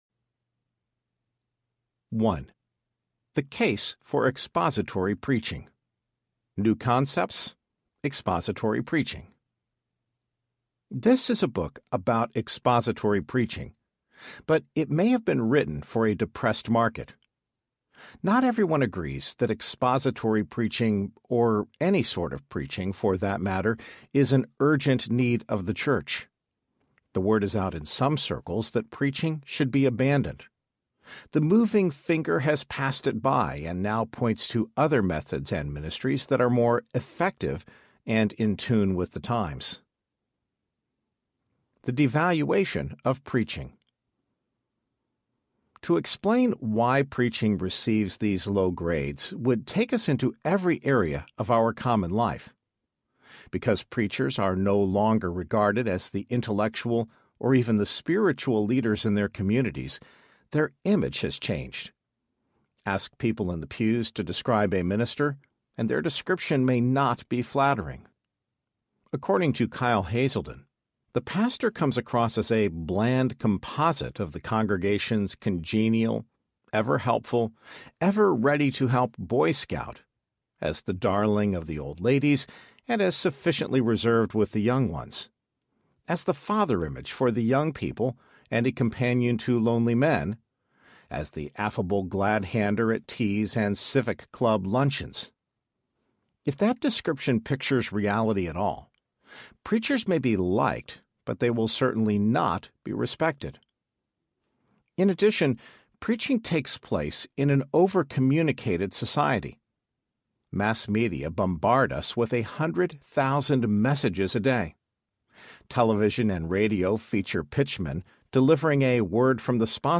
Biblical Preaching Audiobook
7.5 Hrs. – Unabridged